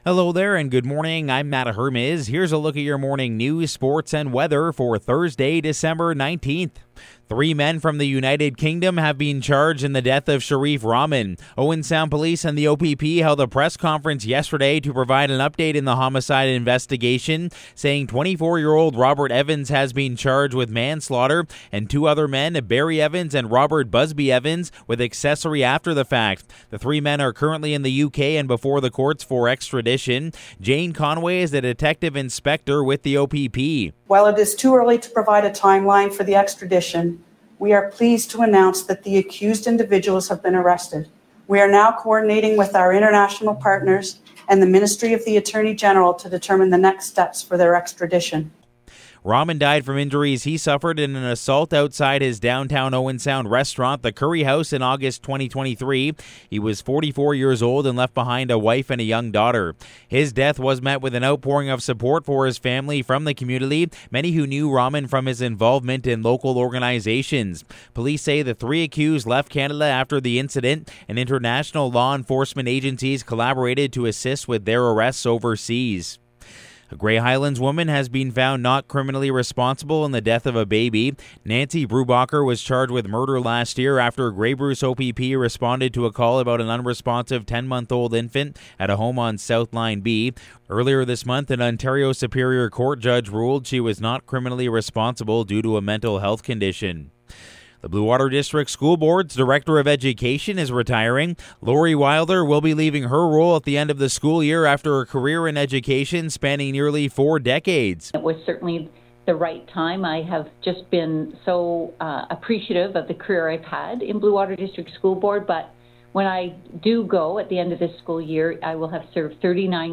Morning News – Thursday, December 19